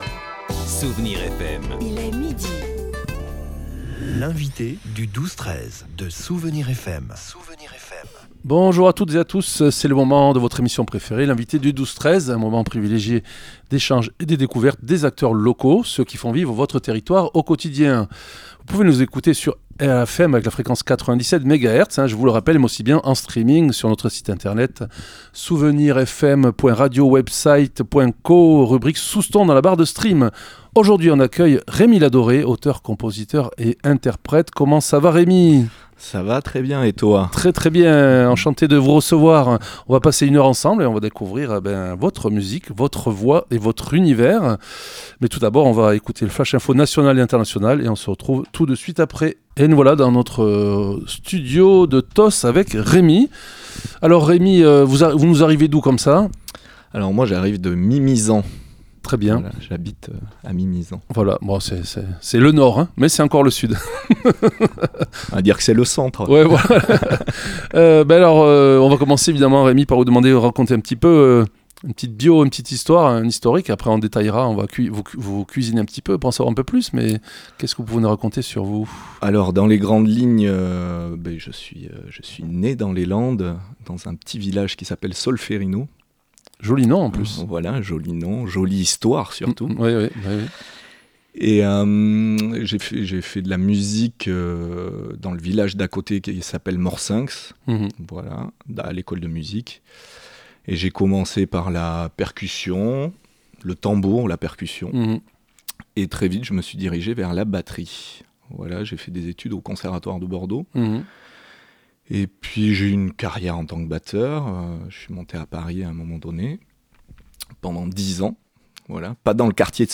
Il nous a interprété en direct deux morceaux dont une de ses compositions . Ecoutez le nous parler de ses aventures et de ses projets.